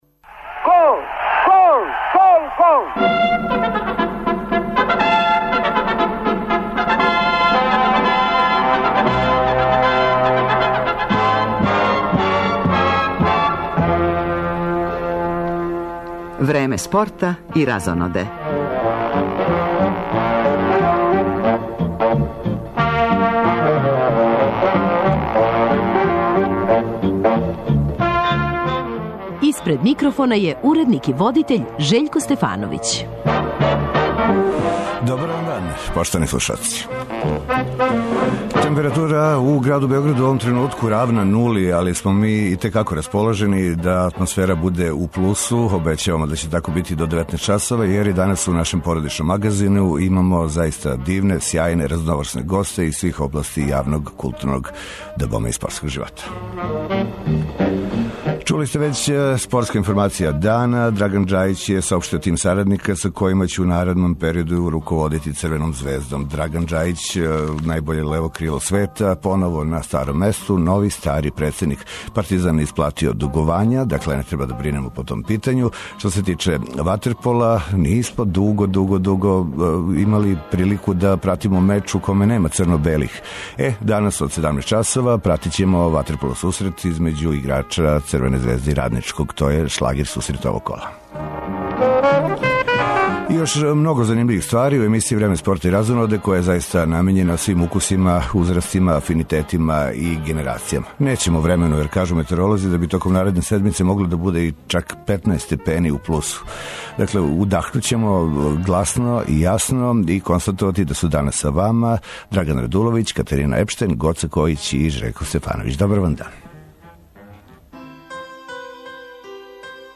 Од 17 часова и 15 минута преносимо дерби сусрет ватерполо лиге Србије, између Црвене звезде и Радничког.